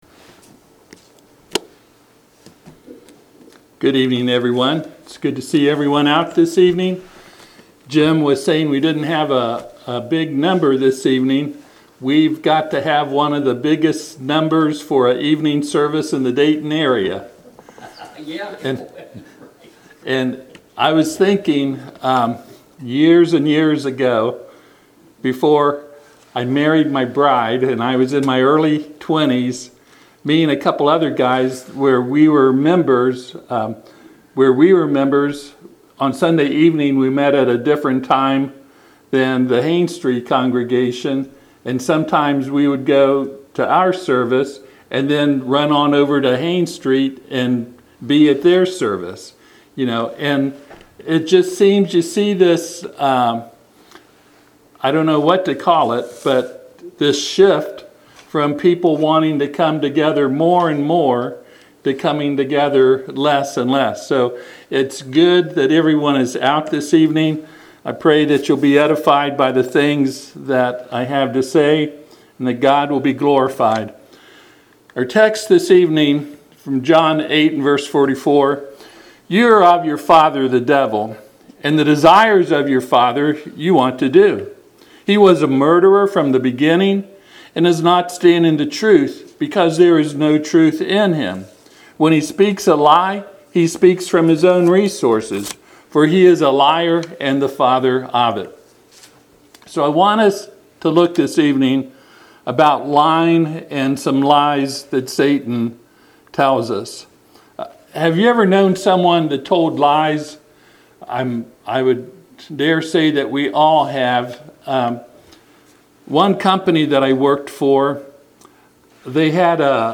Passage: John 8:44-45 Service Type: Sunday PM